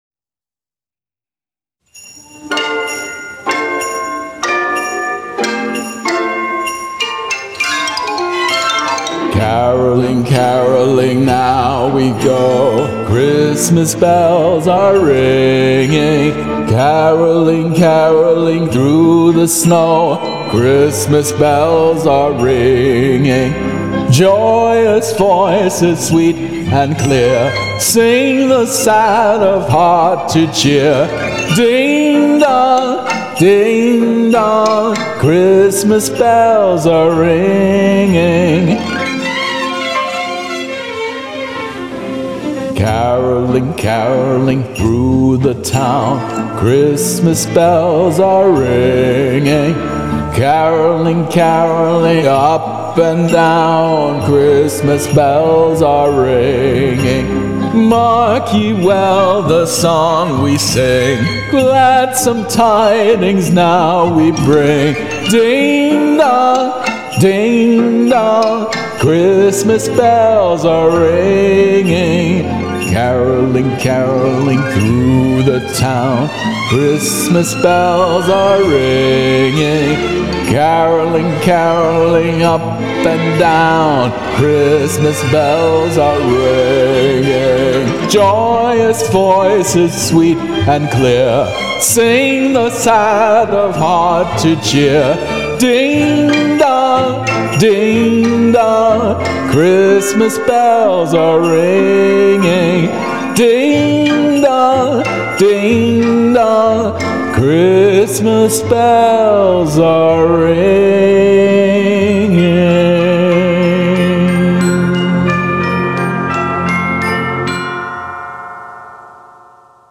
karaoke arrangement
so I'm letting it guide my singing in this cover.